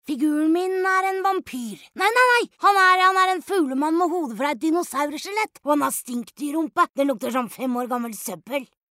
Character Boy